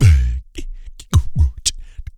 DEEP BREATH2.wav